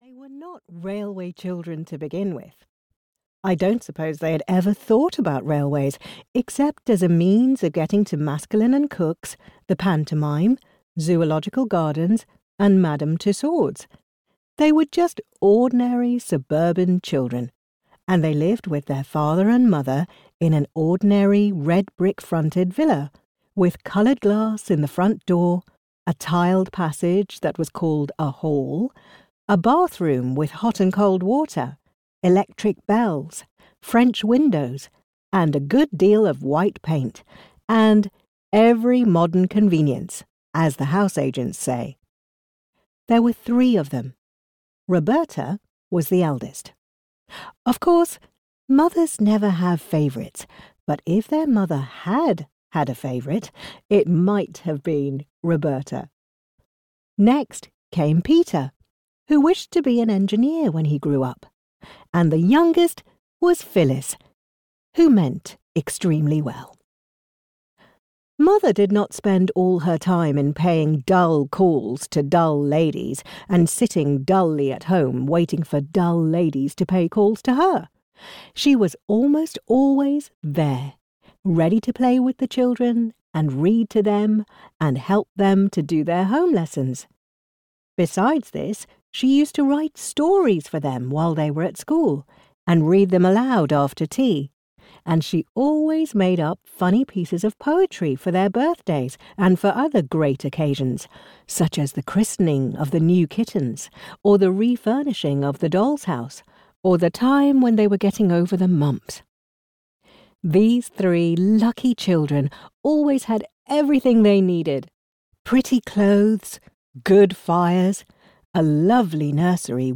The Railway Children - a Children's Classic (EN) audiokniha
Ukázka z knihy